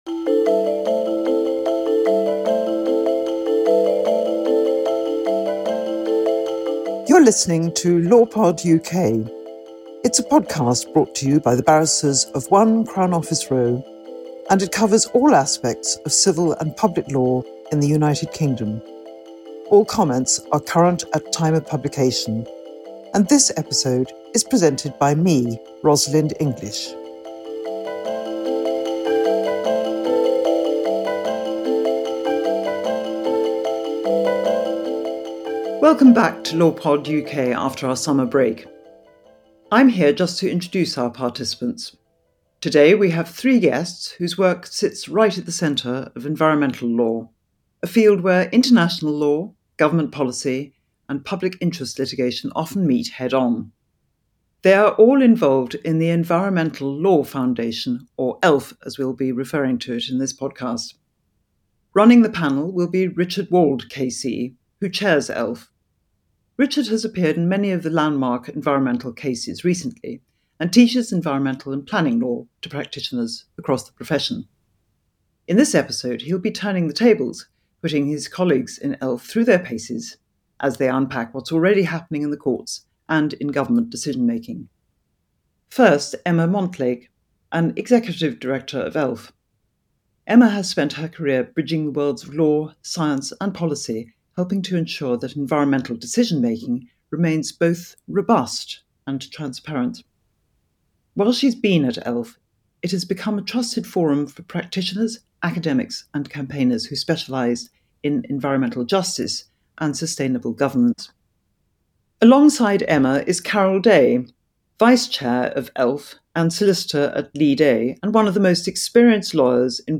a panel of environmental law experts